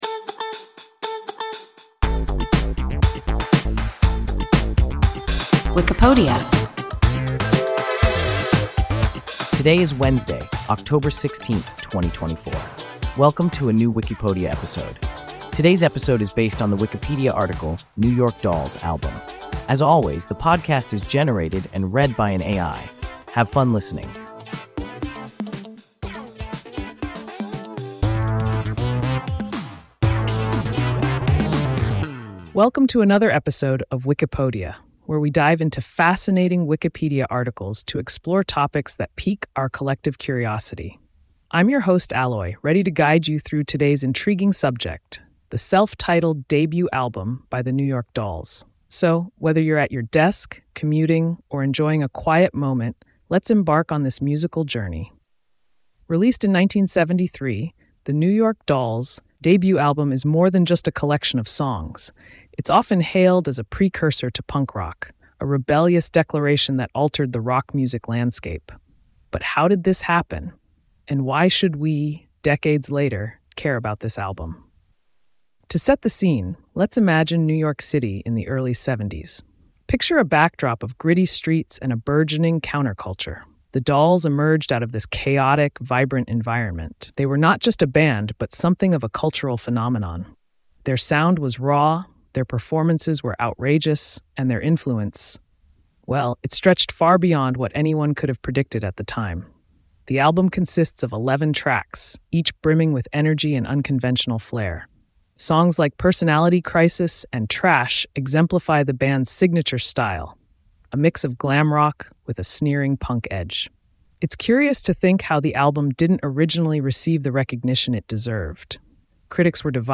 New York Dolls (album) – WIKIPODIA – ein KI Podcast